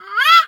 bird_peacock_hurt_02.wav